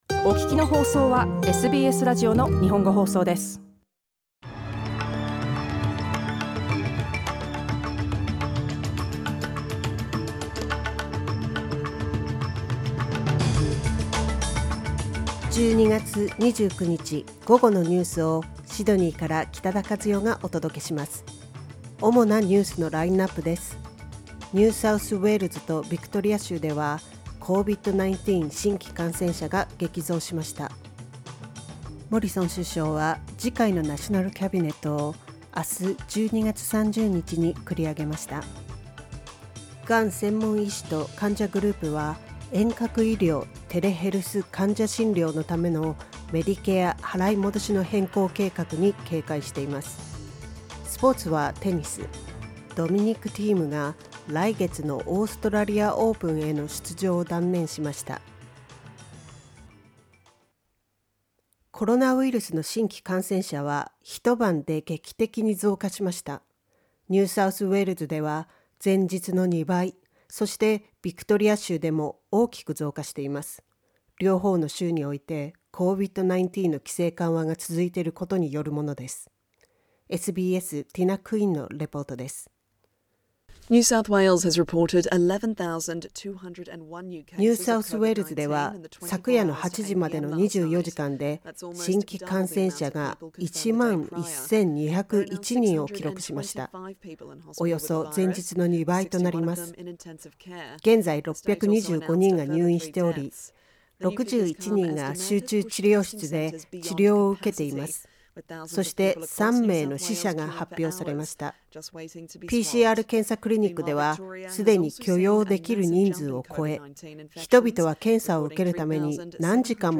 12月29日の午後のニュースです
12月29日水曜日午後のニュースです。Afternoon news in Japanese, 29 December 2021